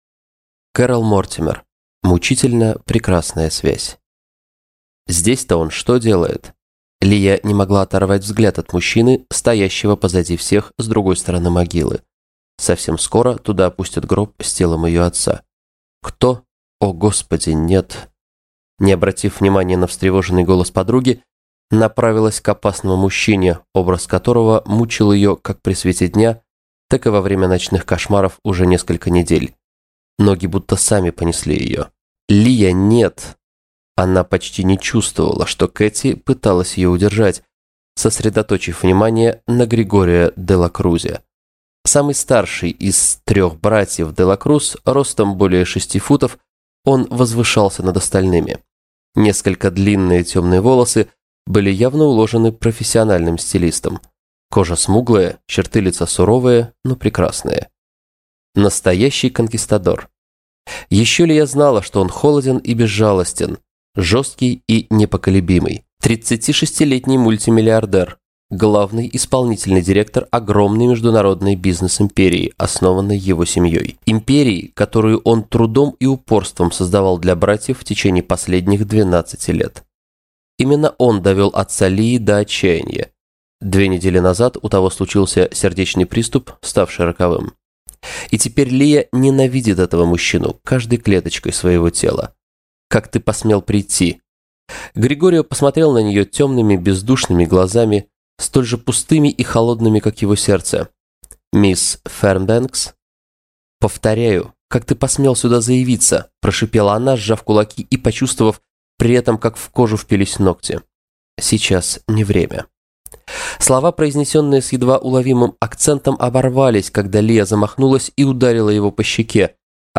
Аудиокнига Мучительно прекрасная связь | Библиотека аудиокниг